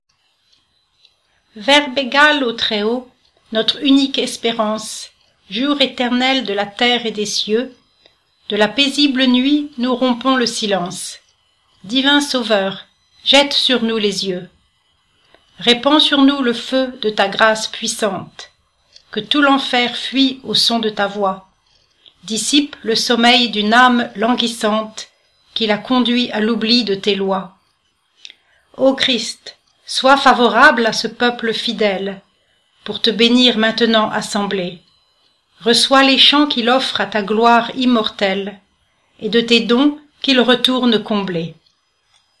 'Real' French Pronunciation:
Faure_Pronunication2.mp3